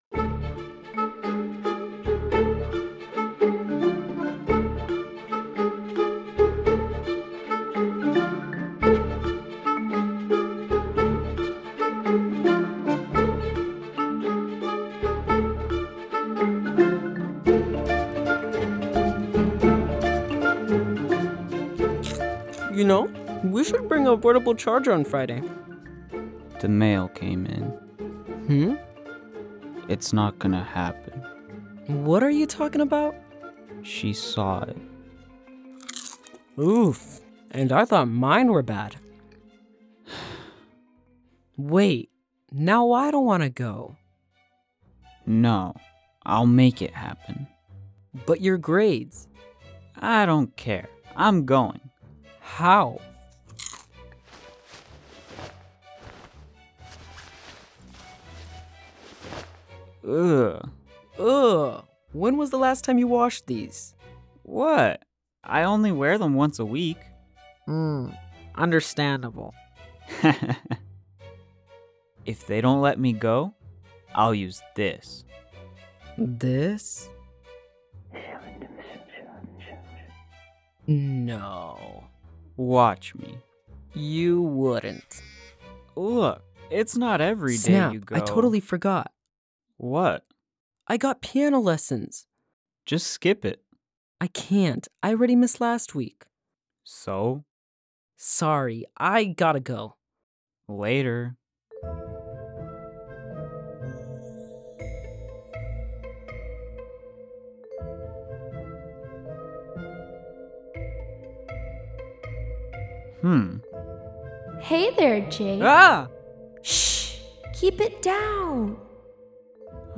Voiceover